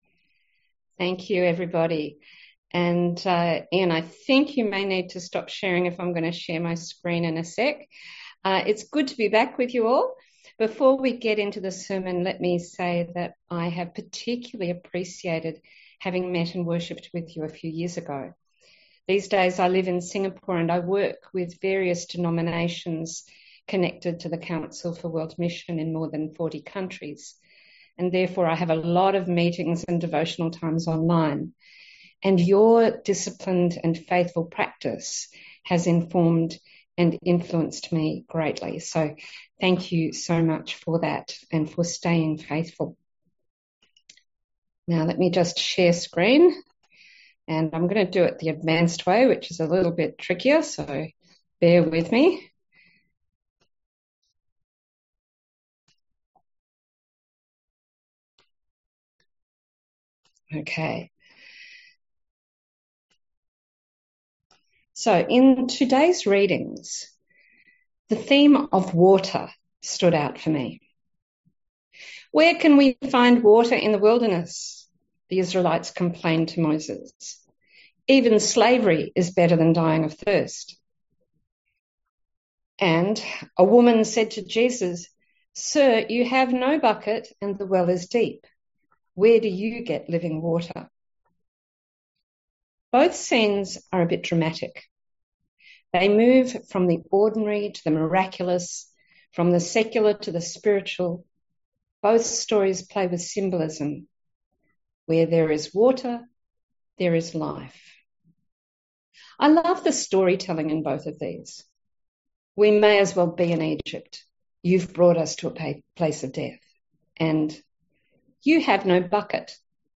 A sermon on Exodus 17:1-7 & John 4:5-42